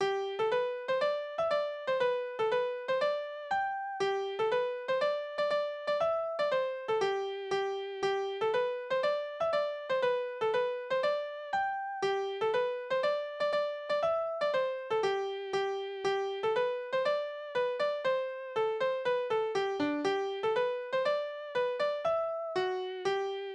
Tonart: D-Dur